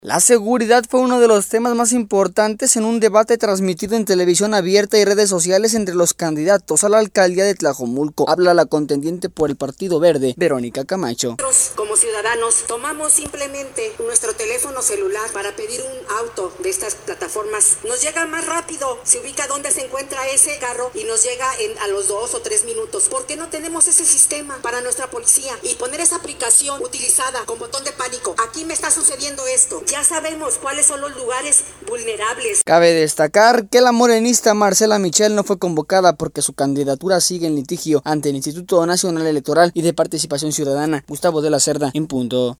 La seguridad fue uno de los temas más importantes en un debate transmitido en televisión abierta y redes sociales, entre los candidatos a la alcaldía de Tlajomulco.